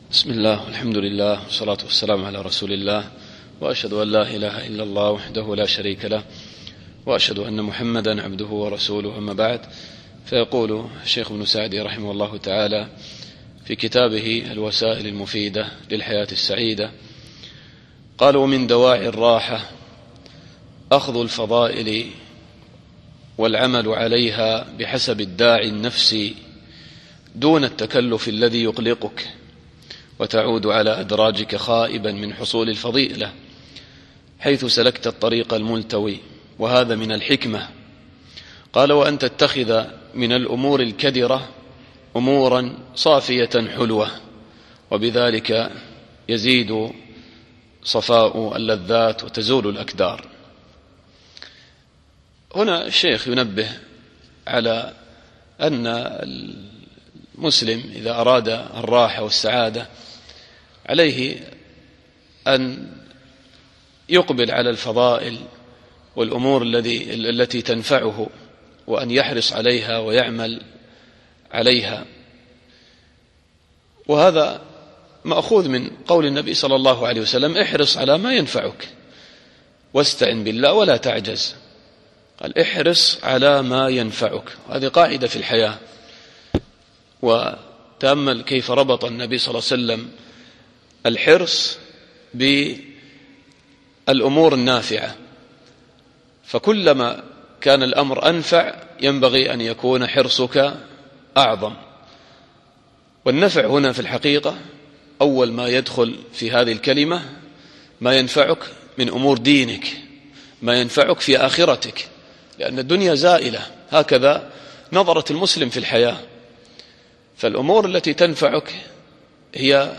الدرس الحادي والعشرون